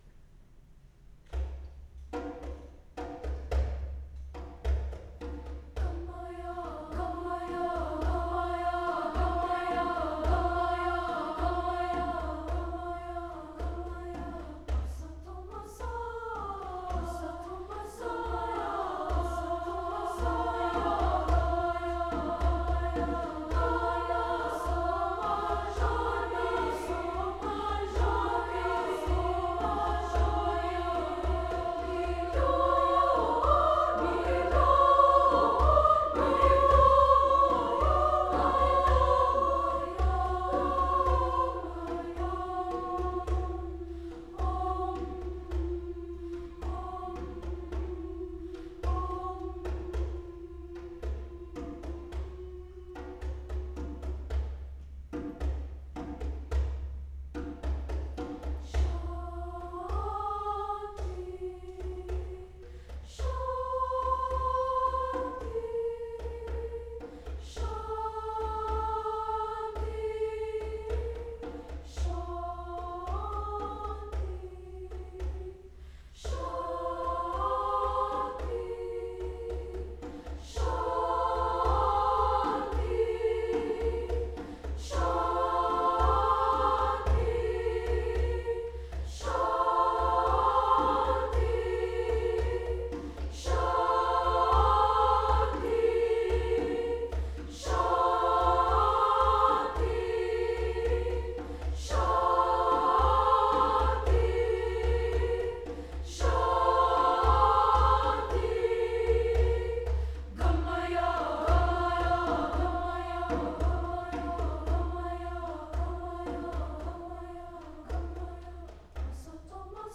A mantra for peace and truth, written in canon.
Equal voices (SA or TB), opt. doumbek